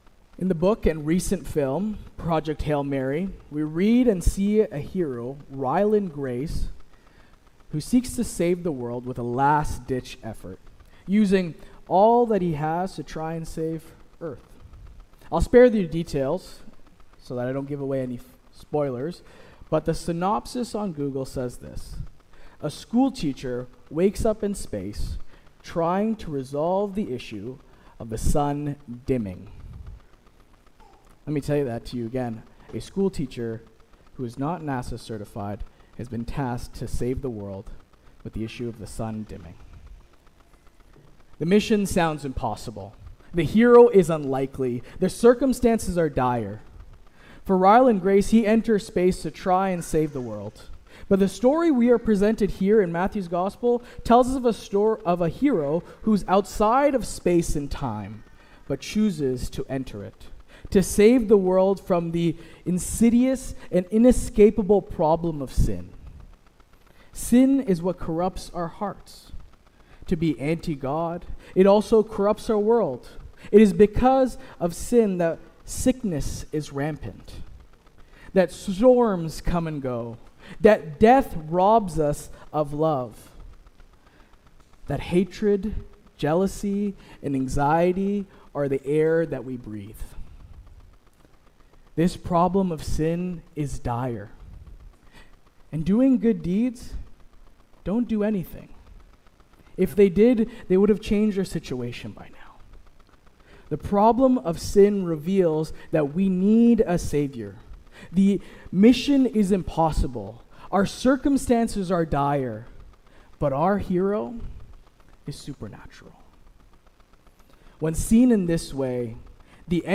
Sermon “Born to Die”